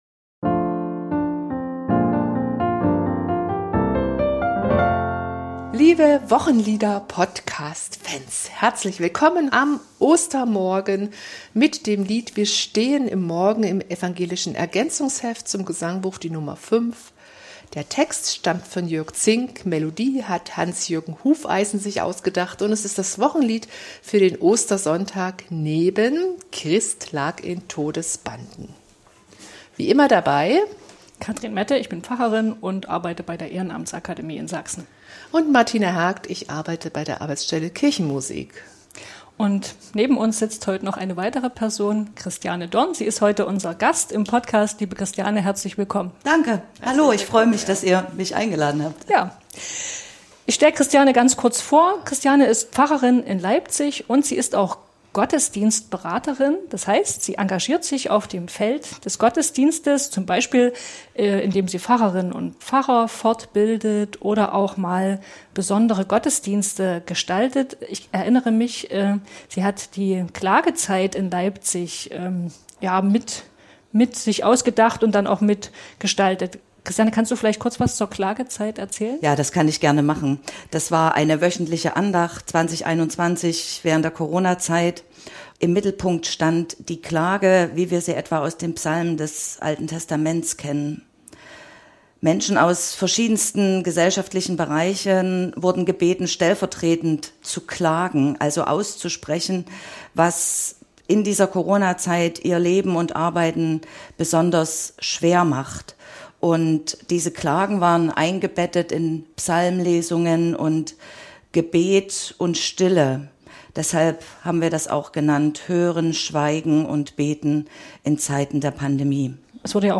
Ein tanzender Puls, im 6/8 Takt. Ein leuchtendes G-Dur. Christus, der sich dreht und lacht und außer sich ist vor österlicher Freude, der uns ansteckt und mitzieht und zum Tanzen bringt - so klingt das Wochenlied für Ostersonntag.